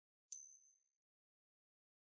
coin-flip.wav